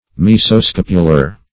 Mesoscapular \Mes`o*scap"u*lar\